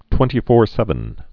(twĕntē-fôr-sĕvən, -fōr-)